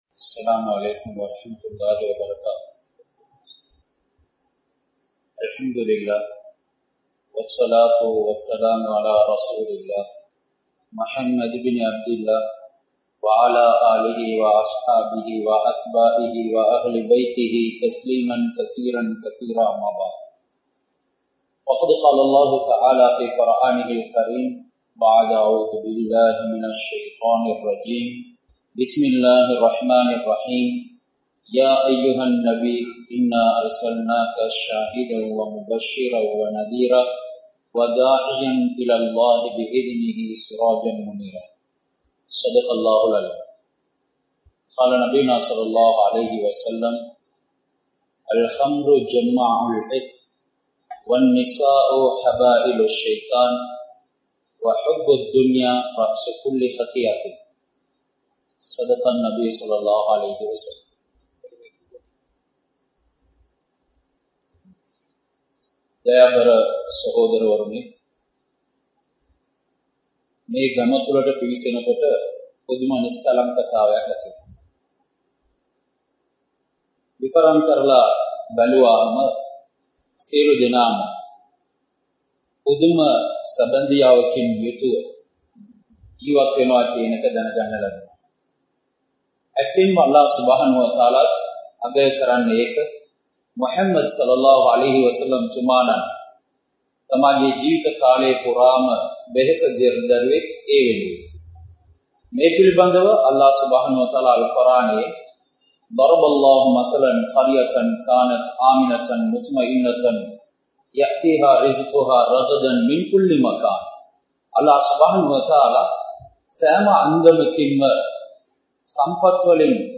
නබ් තුමන් පිලිපදිමු.මතින් තොර සමජයක් ගොඩ නගමු | Audio Bayans | All Ceylon Muslim Youth Community | Addalaichenai